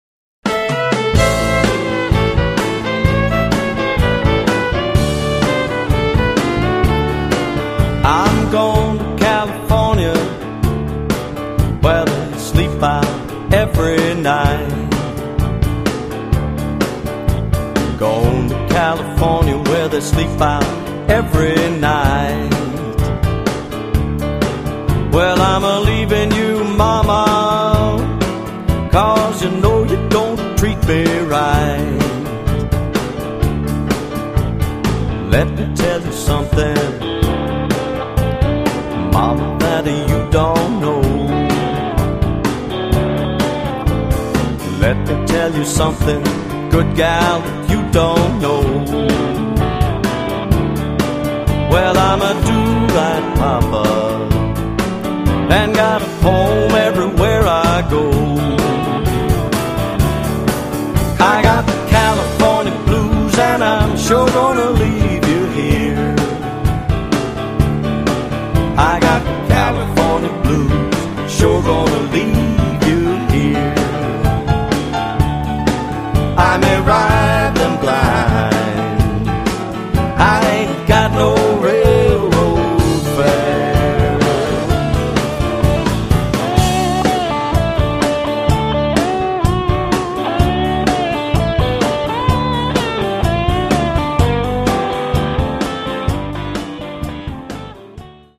The repertoire covers a wide range of blues styles